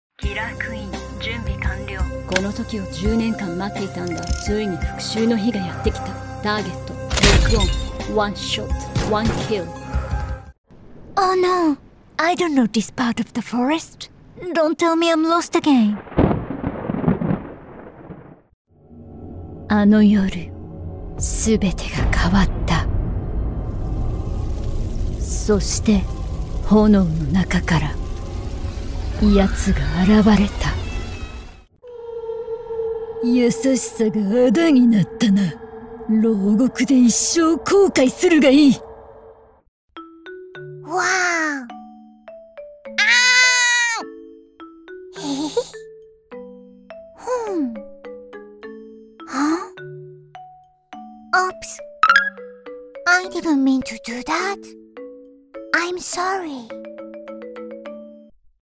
Video Game – Japanese & English | Authentic & Immersive
Neutral international or Japanese-accented English
• Neumann TLM 103 condenser microphone